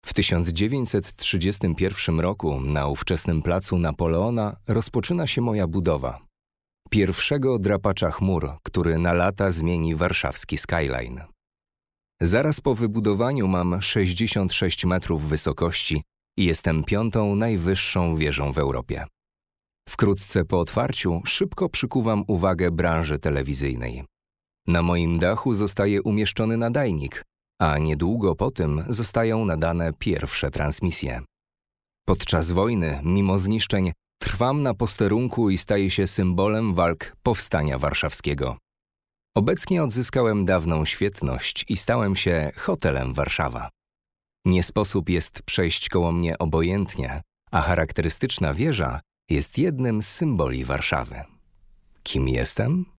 30-50 lat Nagrywa w językach: / polski OD 1100 PLN    |    24h